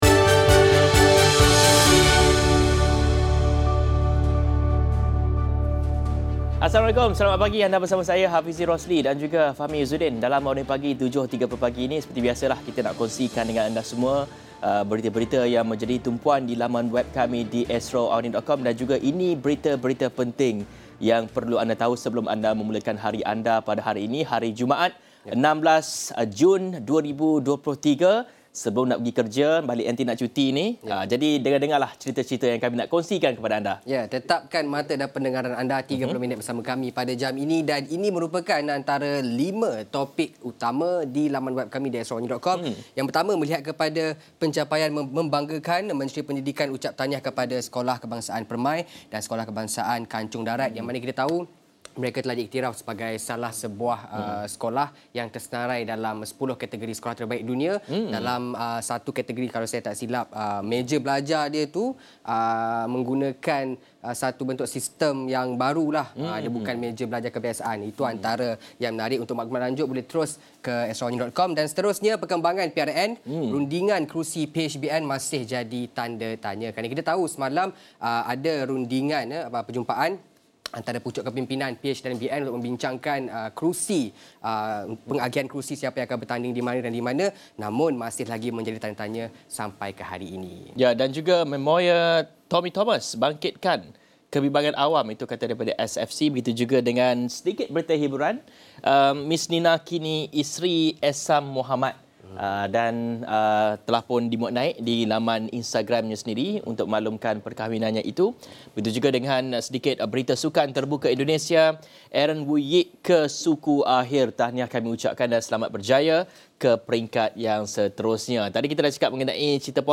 diskusi